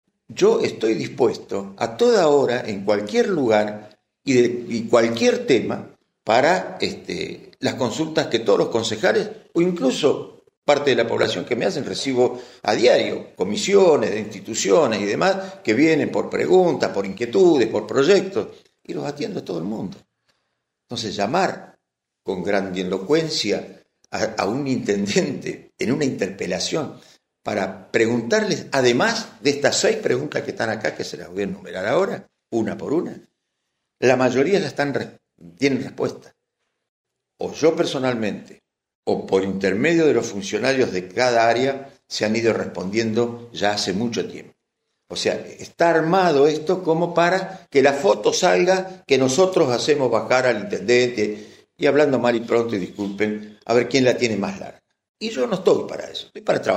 Sanchez-Conf-prensa-sitio.mp3